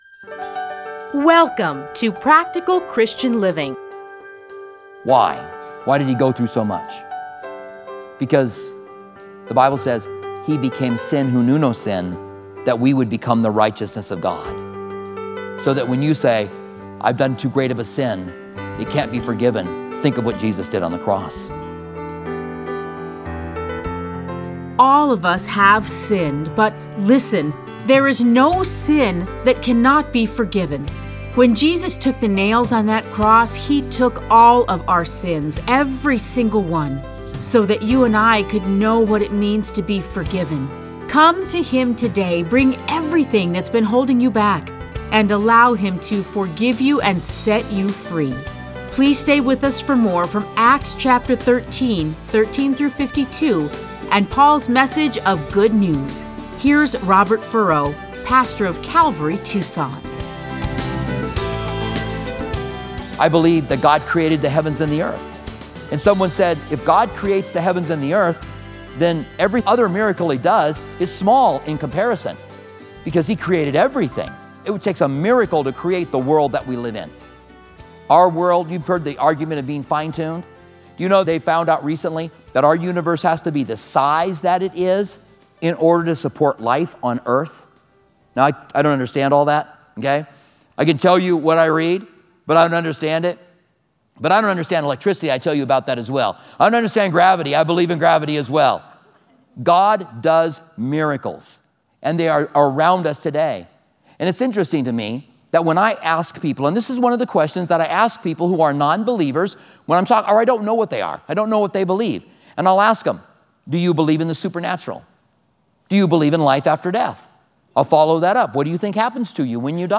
Listen to a teaching from Acts 13:13-52.